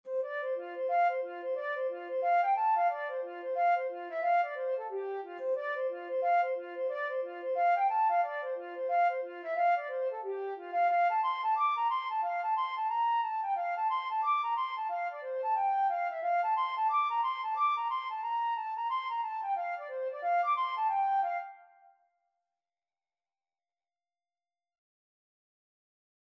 F major (Sounding Pitch) (View more F major Music for Flute )
4/4 (View more 4/4 Music)
F5-D7
Flute  (View more Easy Flute Music)
Traditional (View more Traditional Flute Music)